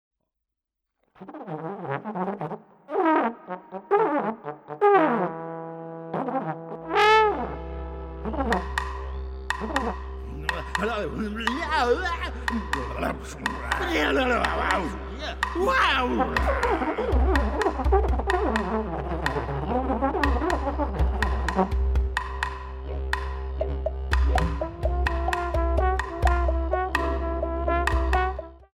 Trombones, Percussion, Voice, Wood Block, Digeridoo